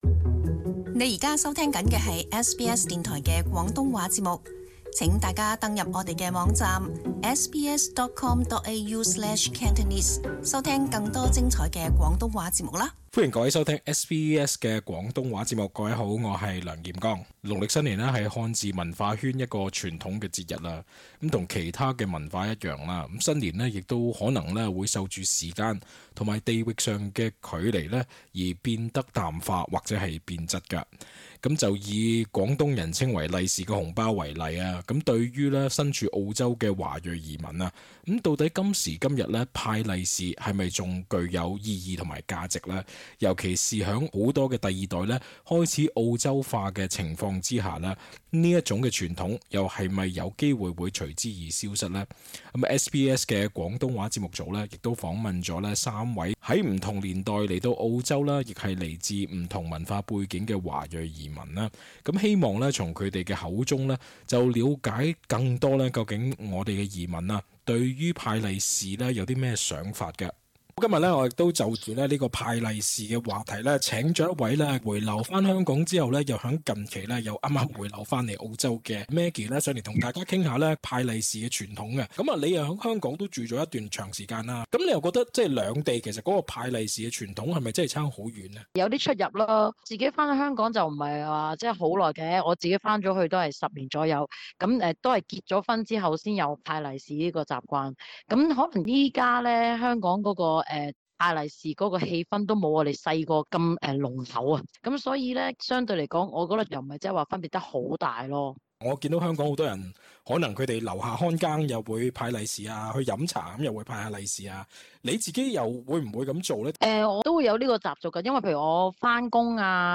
SBS廣東話亦訪問了三位於不同年代來澳洲、亦來自不同文化背景的華裔移民，以了解移民對是否已對派利是的想法有所改變。